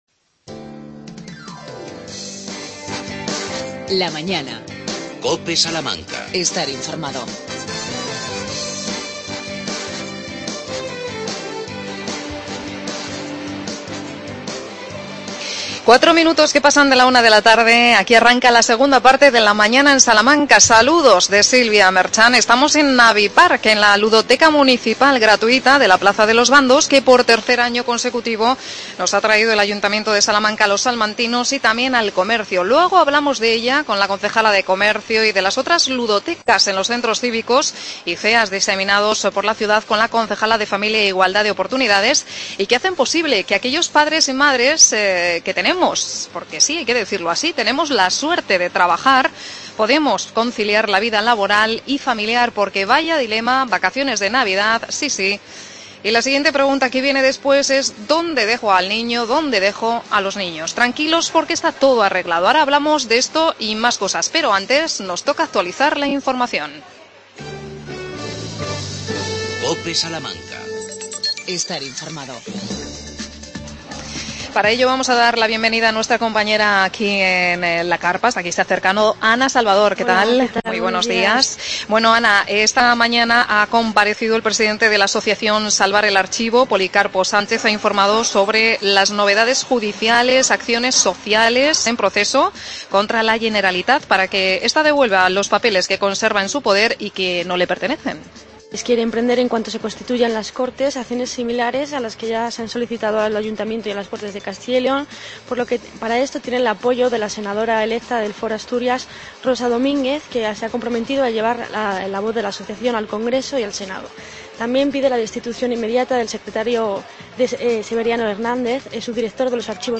AUDIO: Desde la plaza de los Bandos en la carpa Navipark.Actividades municipales navideñas del Ayuntamiento de Salamanca.